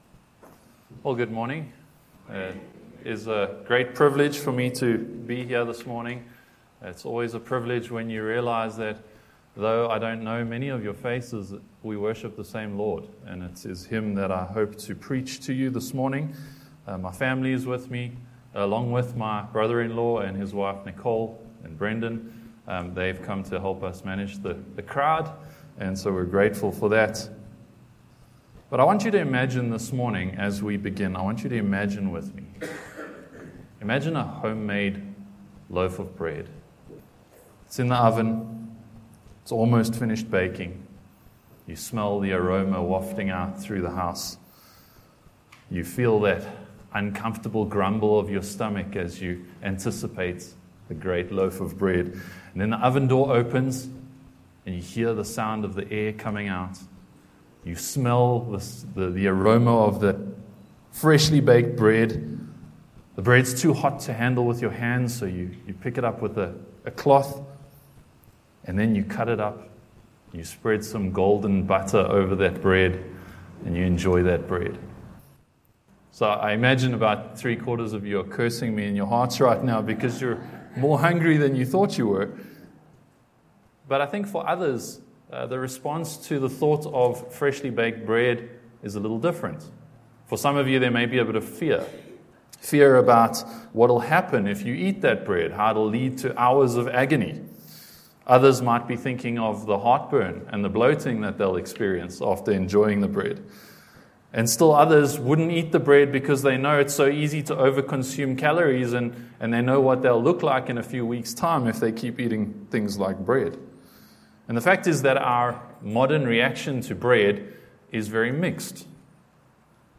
John 6:47-58 Service Type: Morning Passage